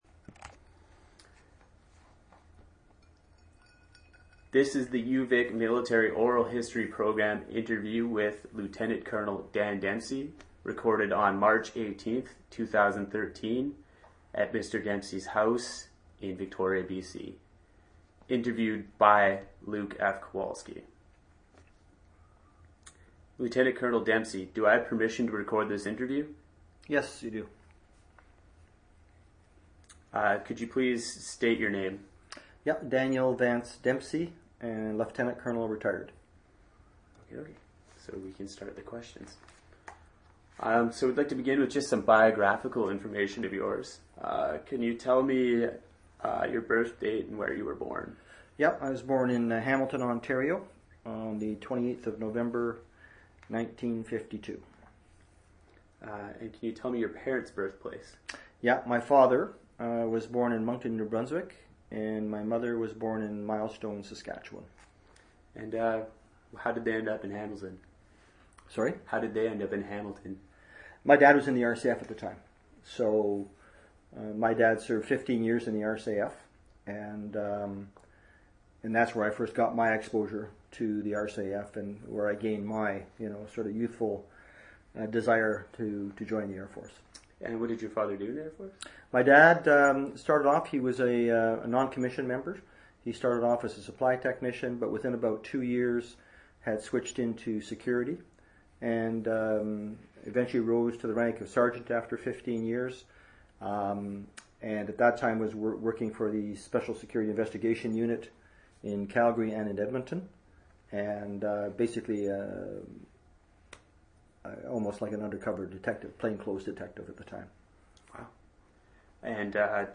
Standard interview narratives.